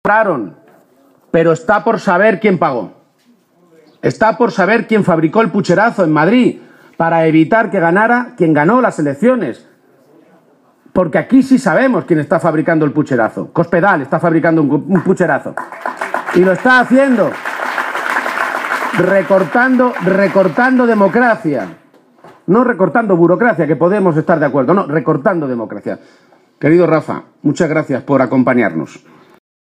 El secretario general del PSOE de Castilla-La Mancha, Emiliano García-Page, ha protagonizado esta tarde un acto electoral en la localidad toledana de Illescas en el que ha dicho que la campaña empieza a ir muy bien para el PSOE y ha animado a oos militantes, simpatizantes y votantes socialistas a seguir “porque a nosotros no nos van a quitar la moral Gobiernos como los de Rajoy y Cospedal, que no tienen moral”.